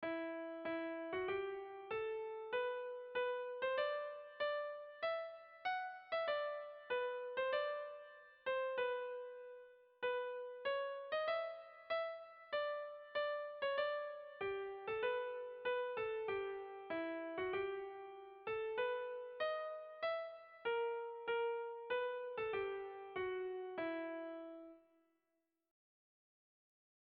Lauko handia (hg) / Bi puntuko handia (ip)